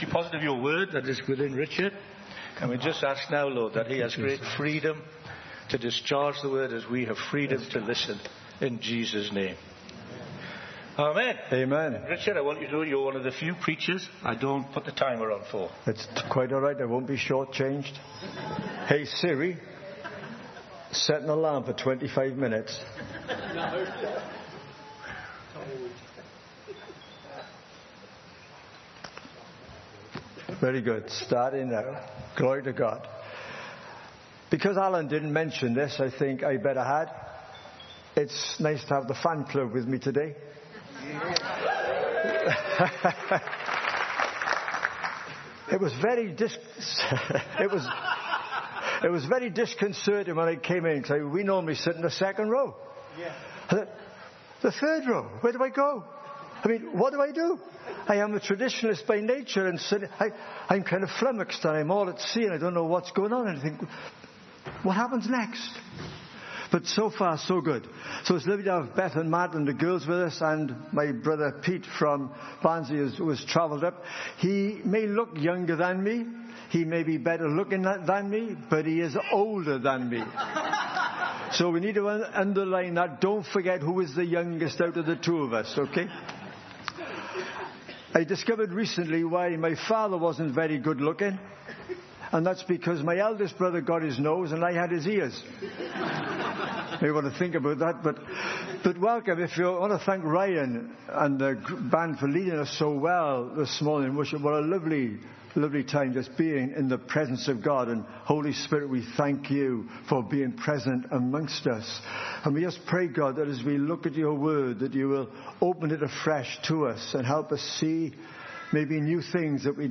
A sermon series at Emmanuel Church Durham from May 2025